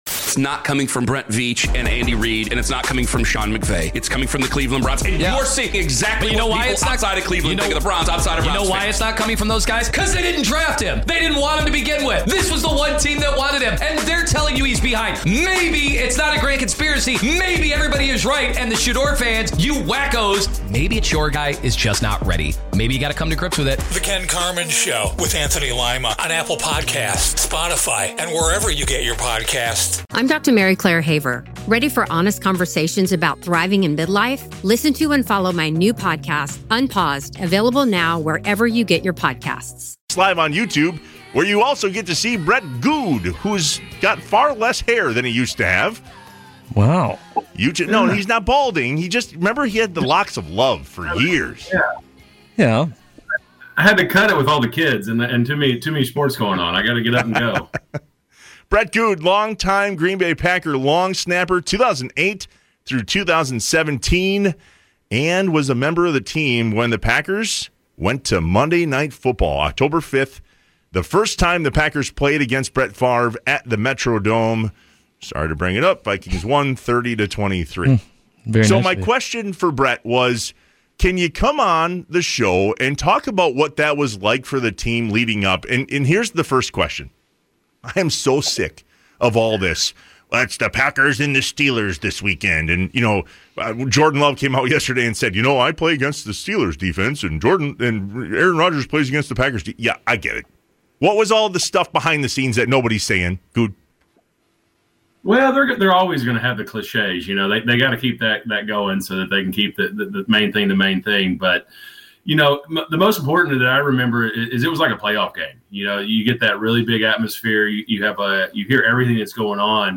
Best Packers Coverage on 105.7FM The FAN.
Also hear players coaches and our Football insiders.